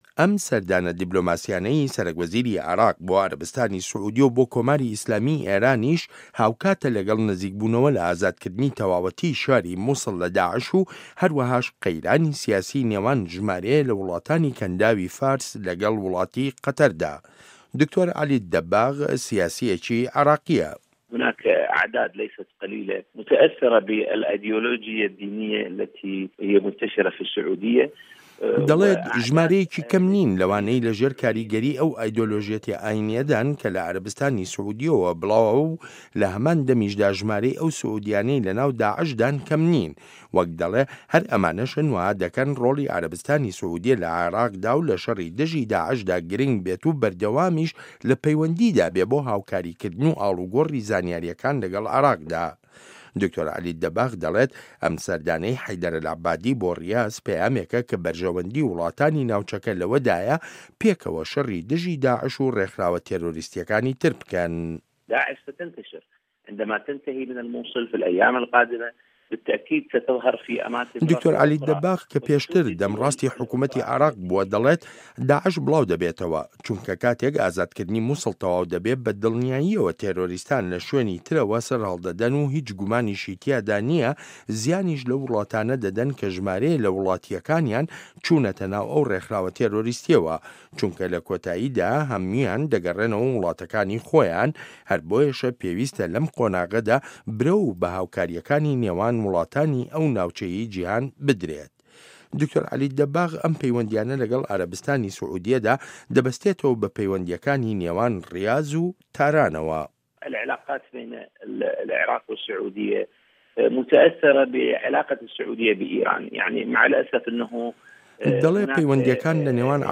ڕاپۆرت لەسەر بنچینەی لێدوانەکانی دکتۆر عەلی ئەلئەدیب